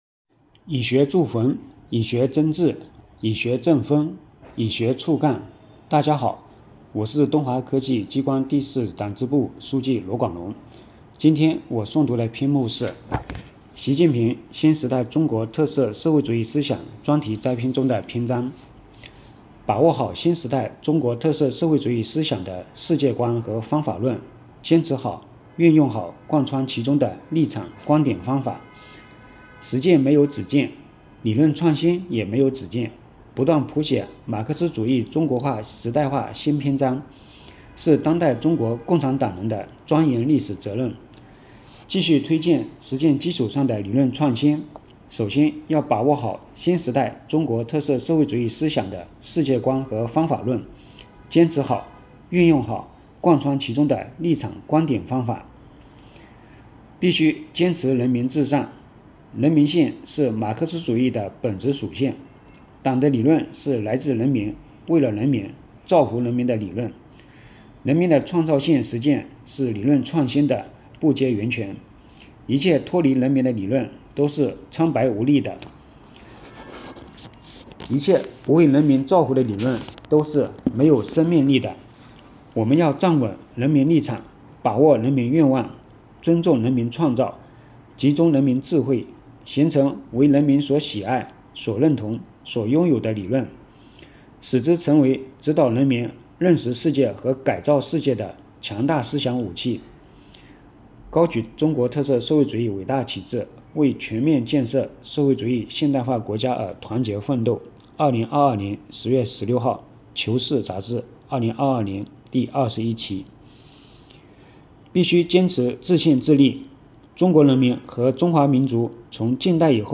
诵读人